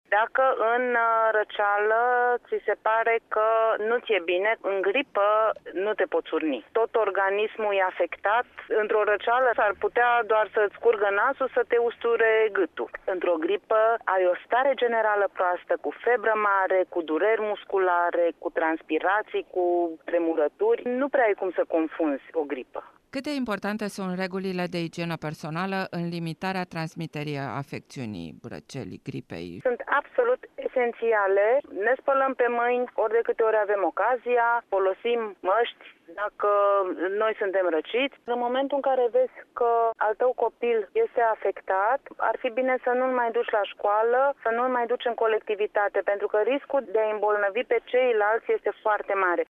Medicul de familie